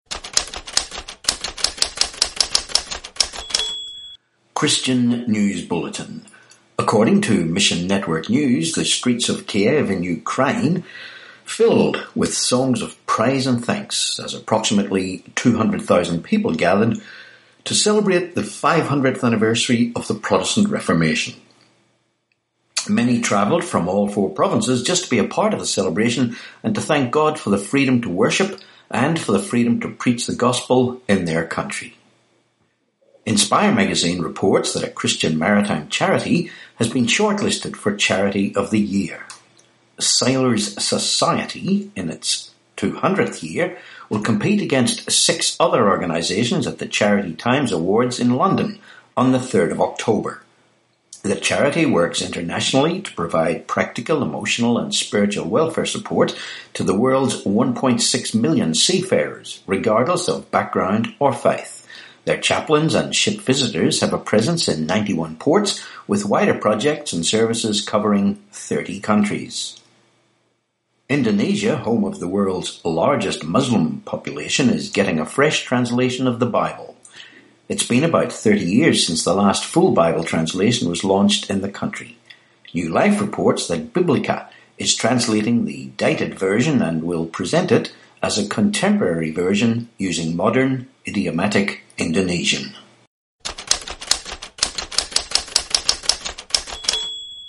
Christian News Bulletin, podcast